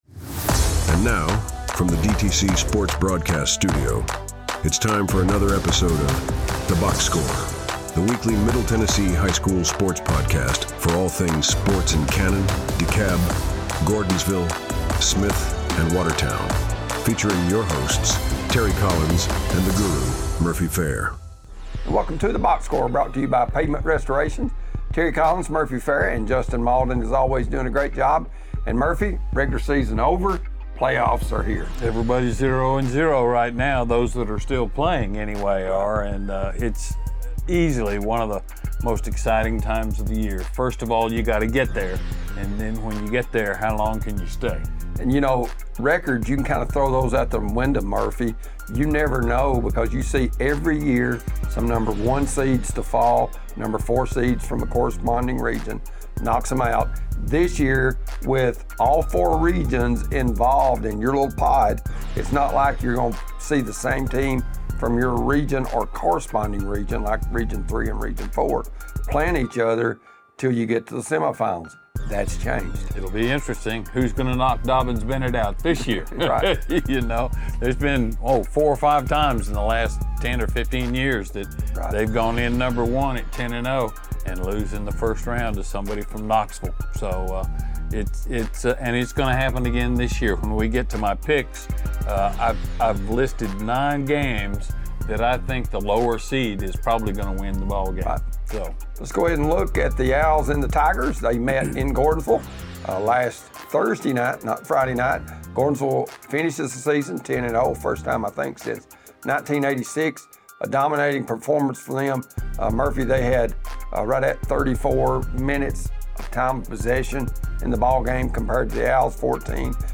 weekly sports talk show
guest interviews